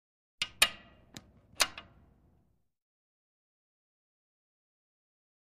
Metal Latch Clicks 3.